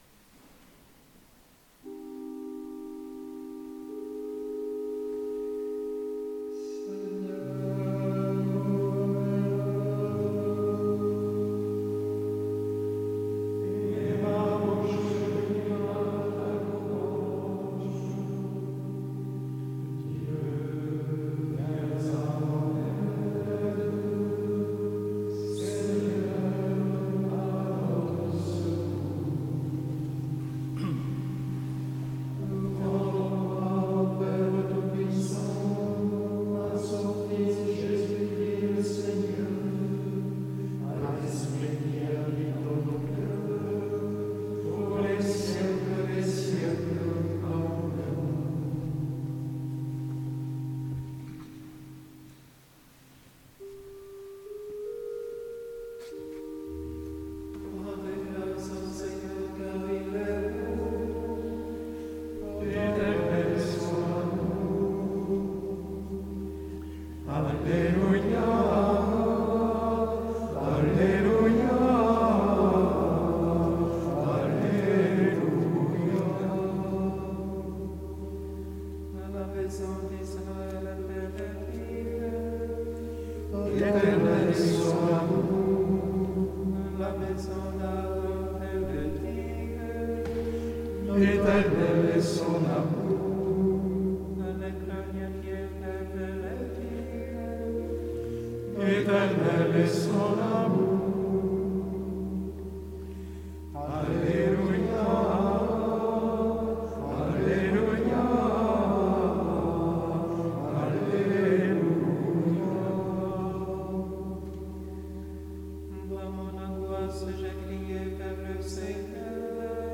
En direct, depuis l’abbaye bénédictine de Tournay dans les Hautes-Pyrénées.